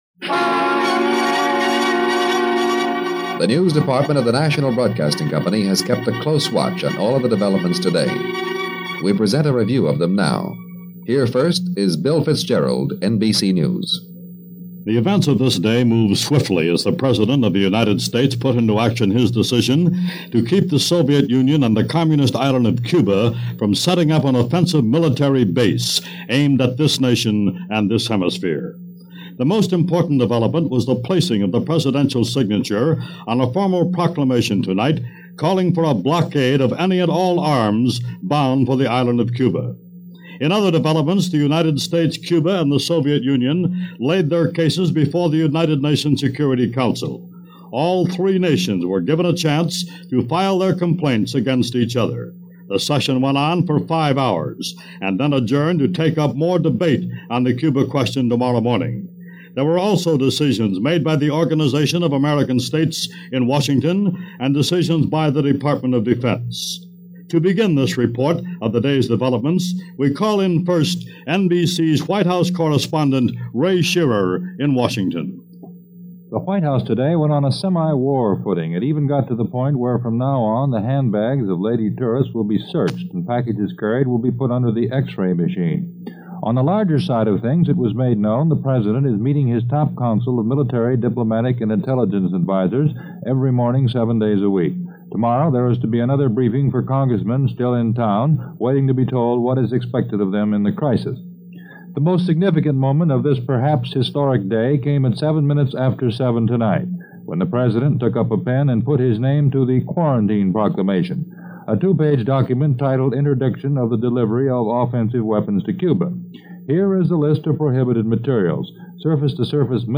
Our featured archival broadcast—a 24-minute NBC Radio Report from October 23, 1962—captures the tension, the diplomacy, and the dread that defined that day.